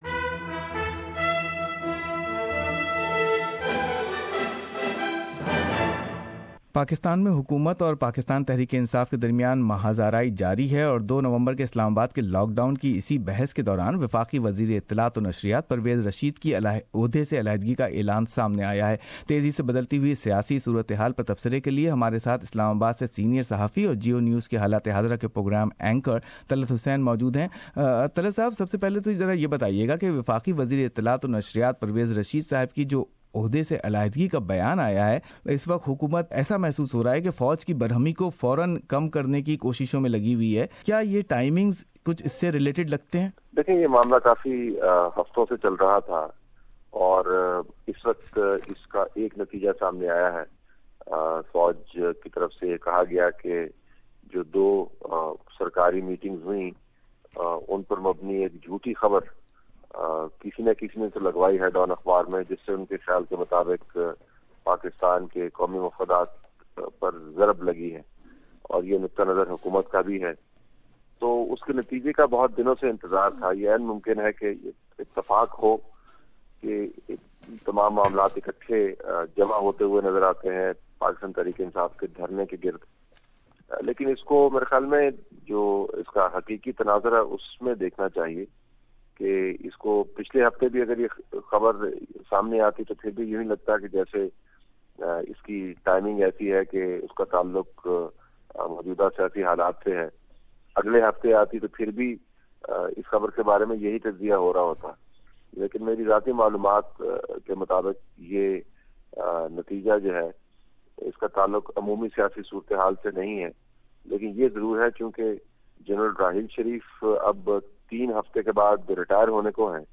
Listen full interview of Talat Hisain.